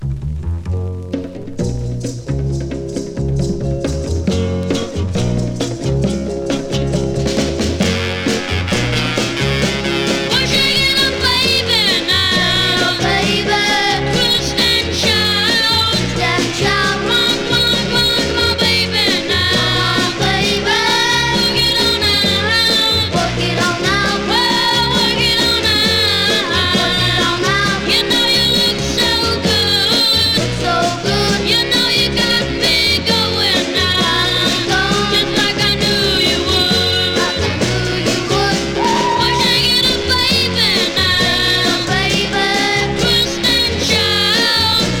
Rock, Pop, Garage　USA　12inchレコード　33rpm　Mono